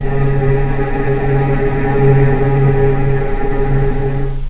healing-soundscapes/Sound Banks/HSS_OP_Pack/Strings/cello/ord/vc-C#3-pp.AIF at 48f255e0b41e8171d9280be2389d1ef0a439d660
vc-C#3-pp.AIF